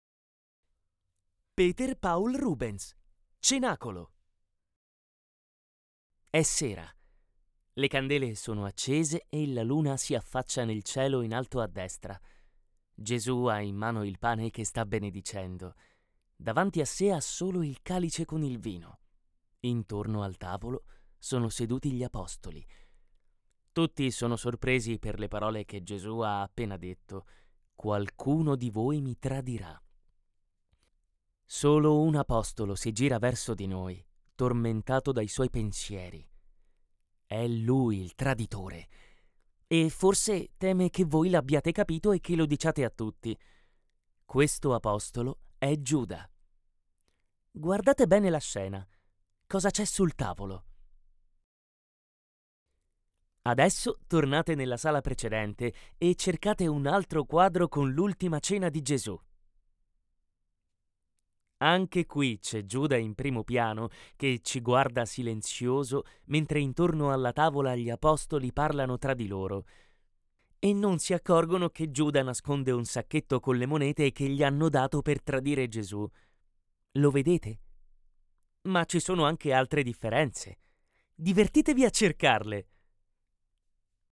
AI Audio
Audioguida "Brera in famiglia"